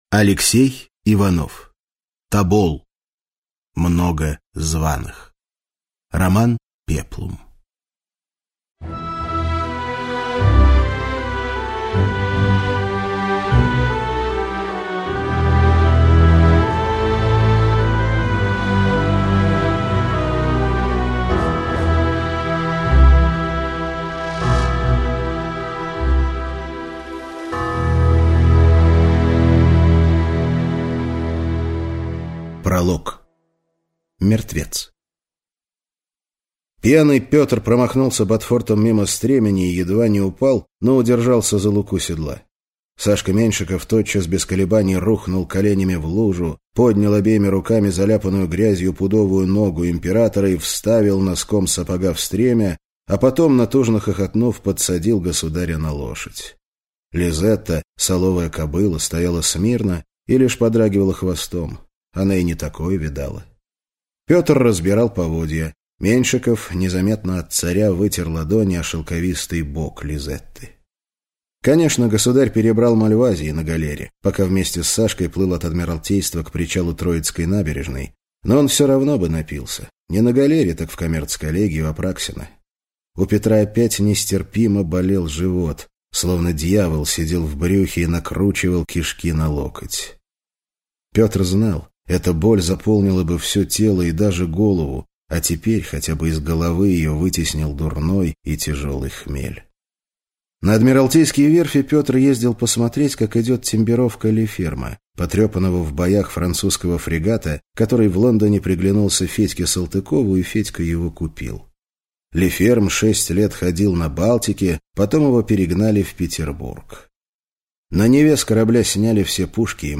Аудиокнига Тобол. Много званых - купить, скачать и слушать онлайн | КнигоПоиск